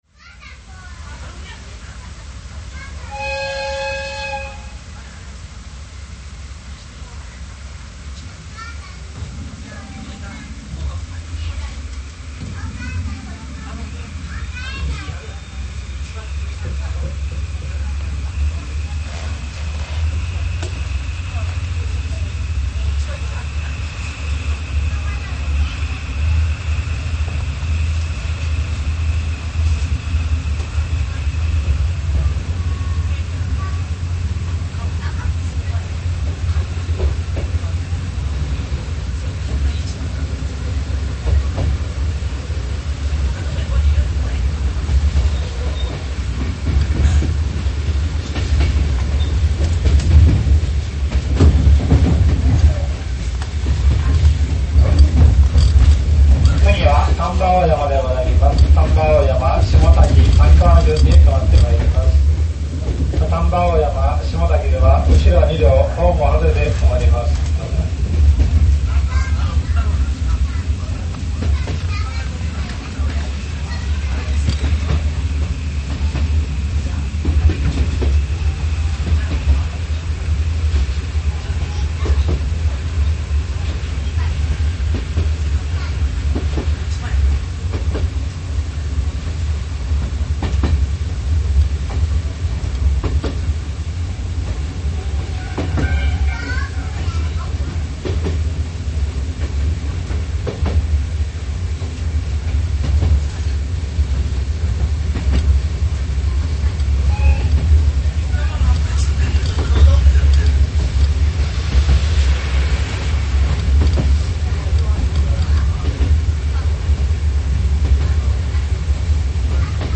ＤＤ51　ナハフ1026　福知山線725レ　篠山口～丹波大山　昭和57年12月31日  （走行音）MP3　　1740ＫＢ　3分50秒
ＤＤ51というより、鳥取行きの鈍行に都落ちしたナハフ10の音です。